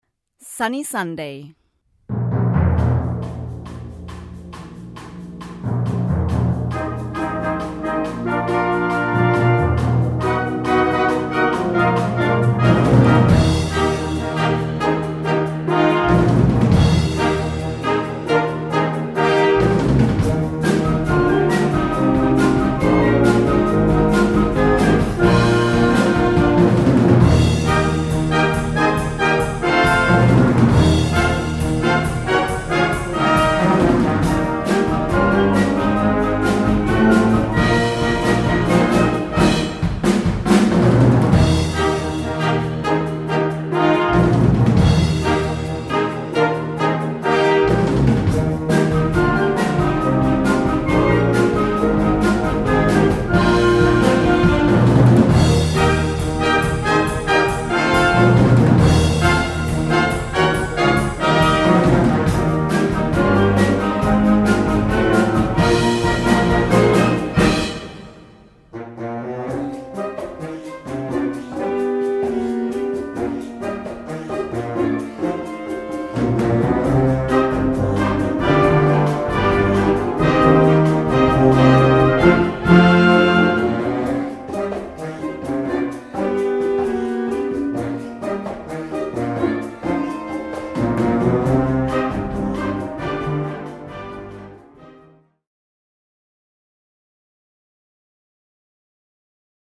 Gattung: Latin Rock
Besetzung: Blasorchester